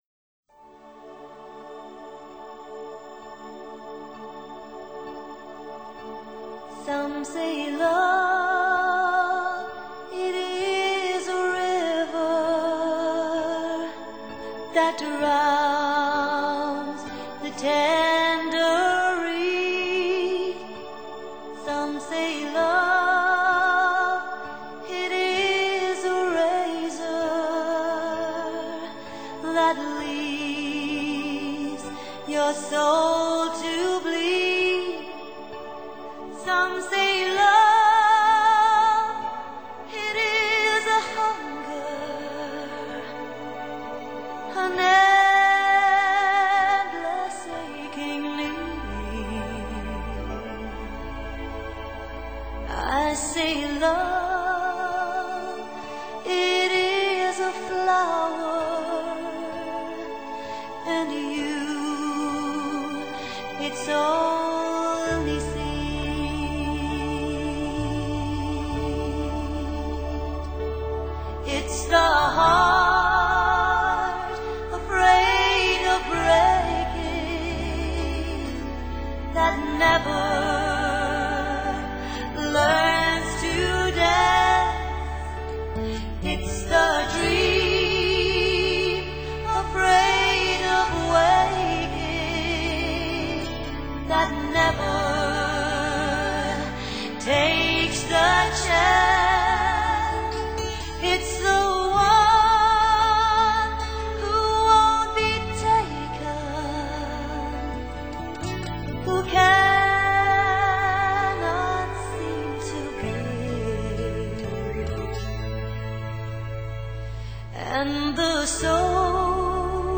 收录九个国家192 首经典情歌，法国、苏格兰、英格兰、德国、意大利、西班牙、魁北克、爱尔兰、比利时。